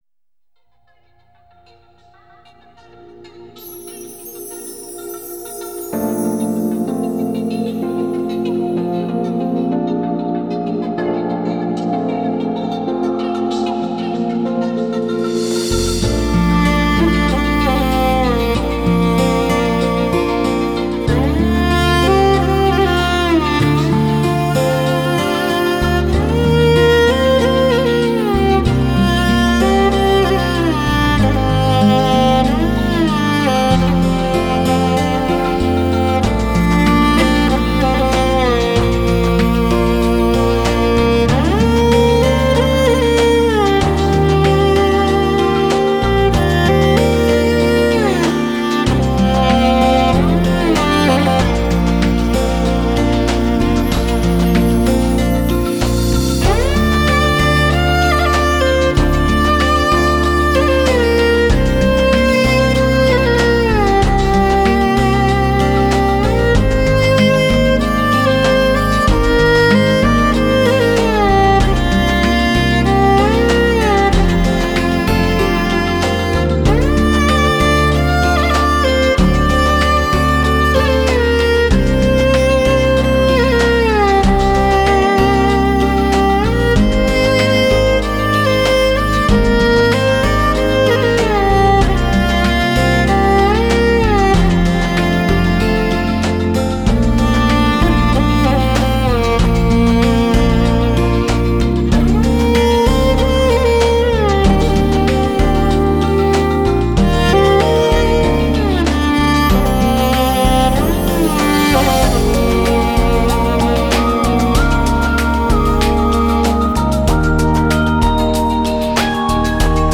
【马头琴】中国指定国家遗产 相传起源于成吉思汗时代，距今已有八百多年历史